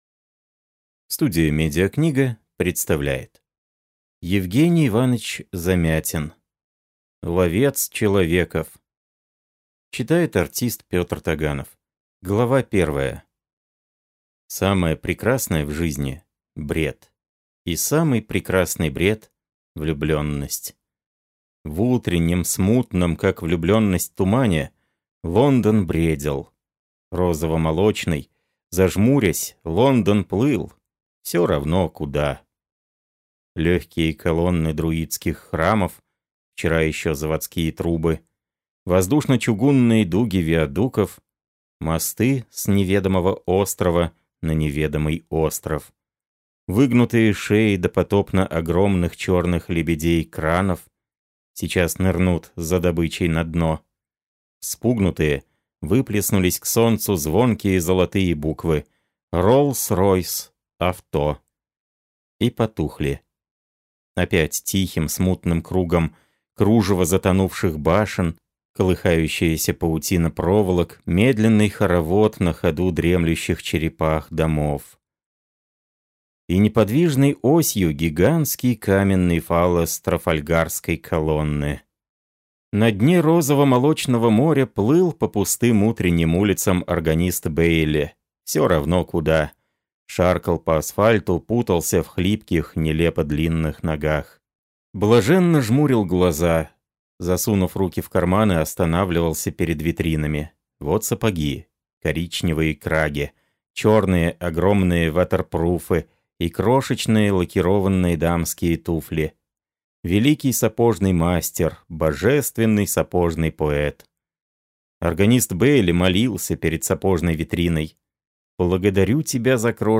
Аудиокнига Ловец человеков | Библиотека аудиокниг